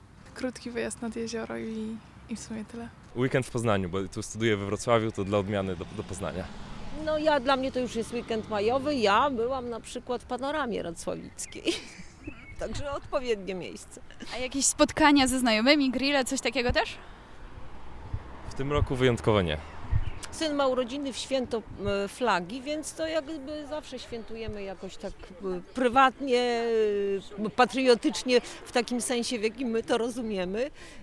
3.sonda_plany-na-majowke.mp3